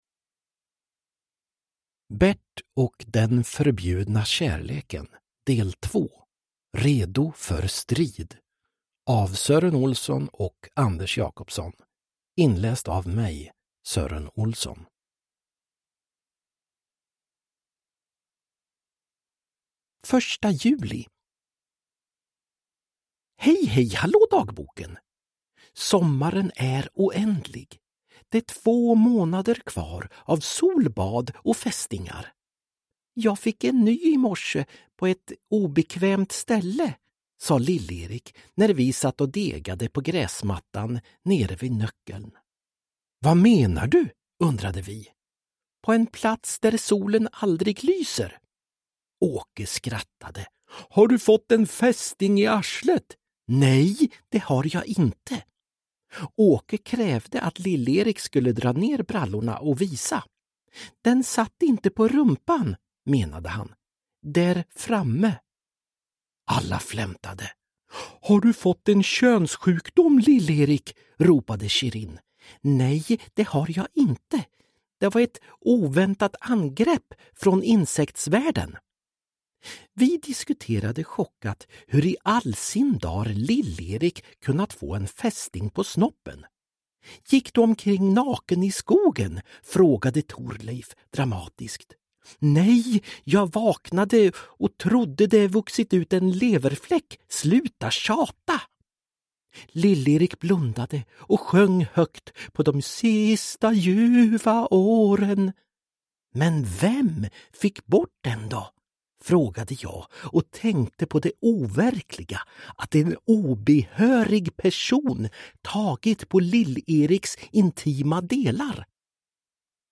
Bert och den förbjudna kärleken, del 2 – Ljudbok – Laddas ner
Uppläsare: Sören Olsson